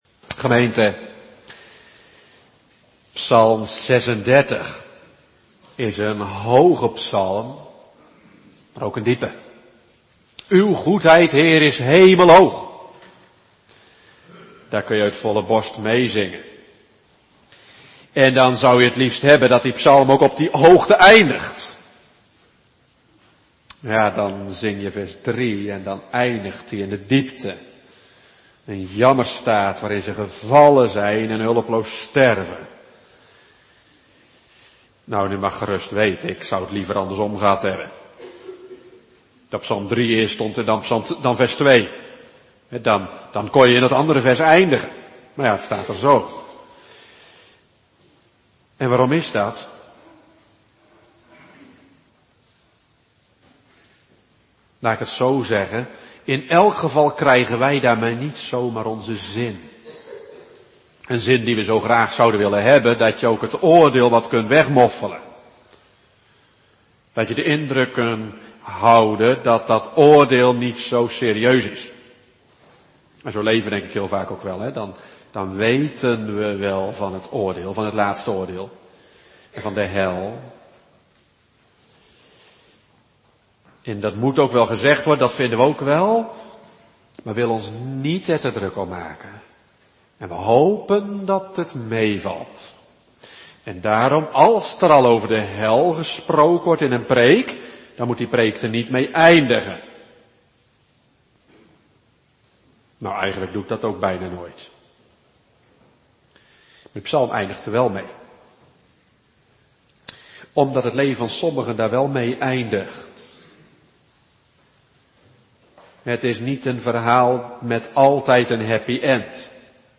24 oktober 2021 Genesis 9:13 Predikant